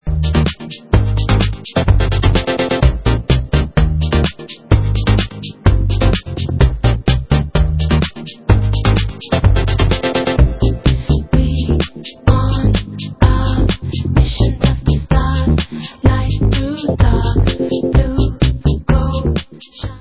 sledovat novinky v oddělení Dance/Breakbeats